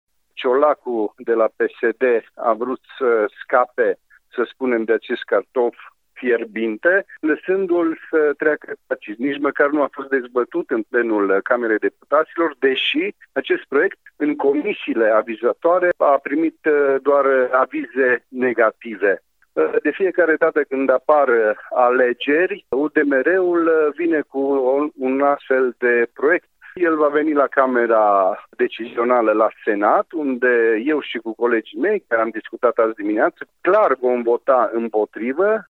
Având în vedere toate avizele negative primite până acum, acest proiect nu va trece de Senat care este cameră decizională în acest caz, a declarat înainte de intra în ședință senatorul PNL de Mureș, Cristian Chirteș.